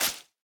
Minecraft Version Minecraft Version latest Latest Release | Latest Snapshot latest / assets / minecraft / sounds / block / sponge / wet_sponge / step1.ogg Compare With Compare With Latest Release | Latest Snapshot